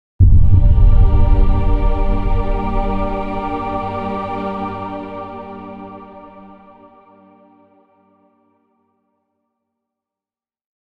Epic-trailer-cinematic-single-hit-sound-effect.mp3